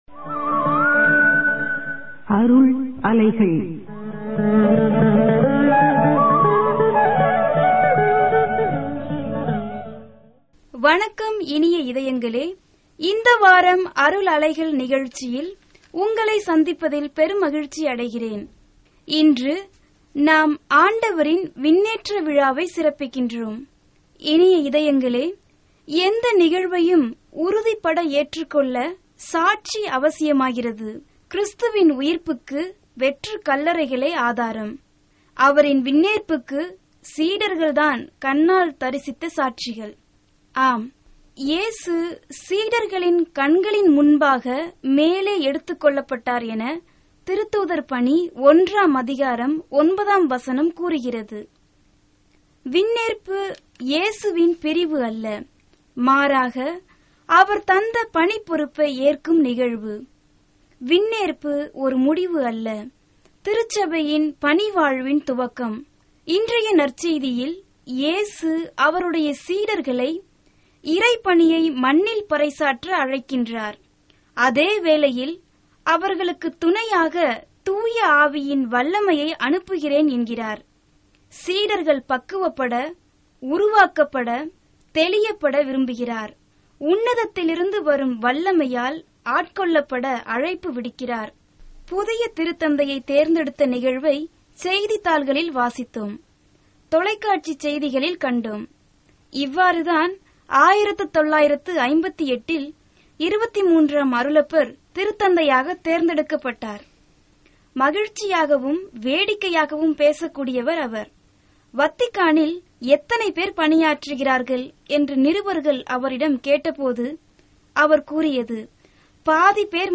Directory Listing of mp3files/Tamil/Homilies/Ordinary Time/ (Tamil Archive)